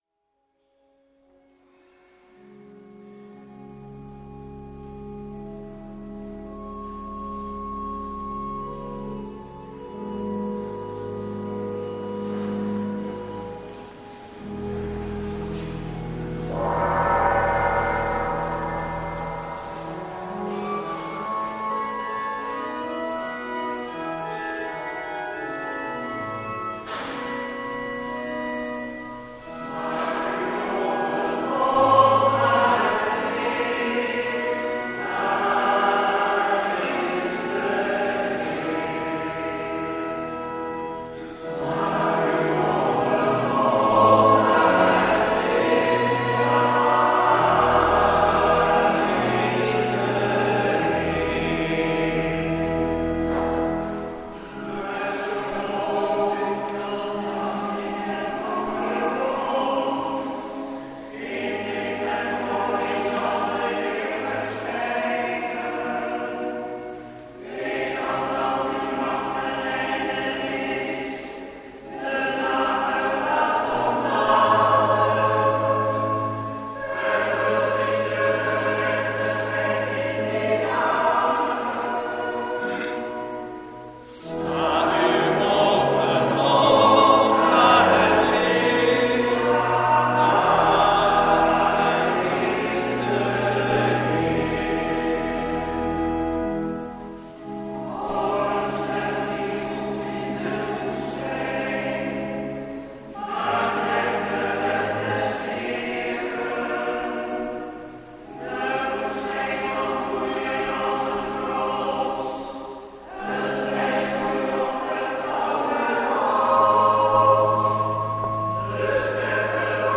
Lezingen
Eucharistieviering vanuit de H. Laurentius te Voorschoten (MP3)